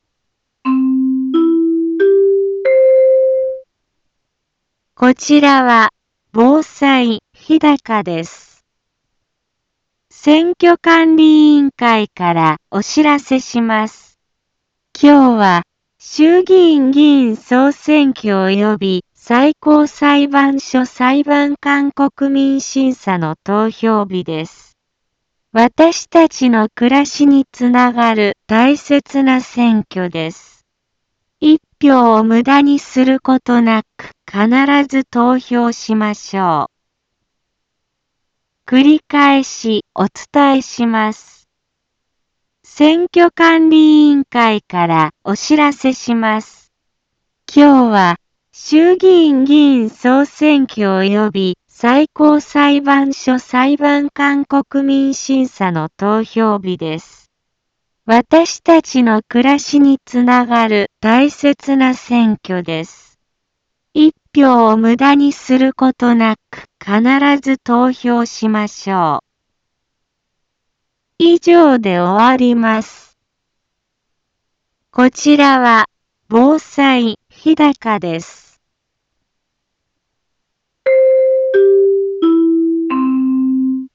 一般放送情報
BO-SAI navi Back Home 一般放送情報 音声放送 再生 一般放送情報 登録日時：2024-10-27 10:03:00 タイトル：衆議院議員総選挙投票棄権防止の呼びかけ インフォメーション： 選挙管理委員会からお知らせします。 今日は、衆議院議員総選挙及び最高裁判所裁判官国民審査の投票日です。